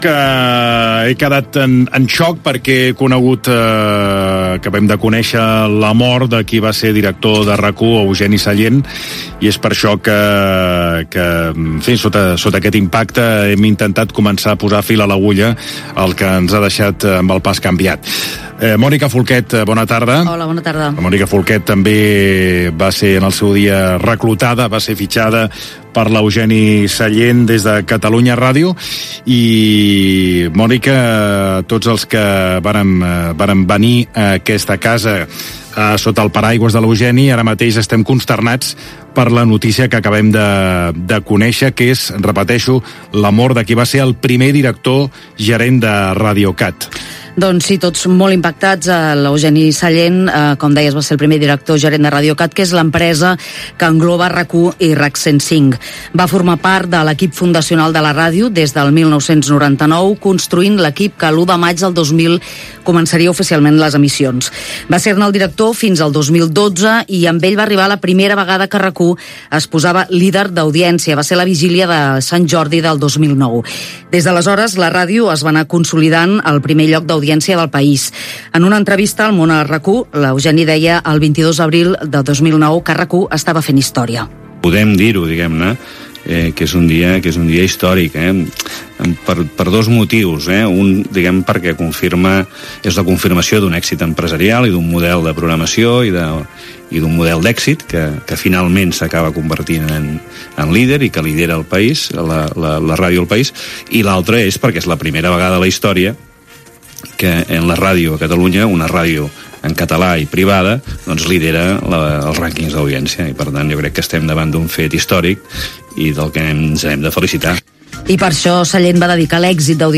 Entreteniment
Fragment extret del web de RAC 1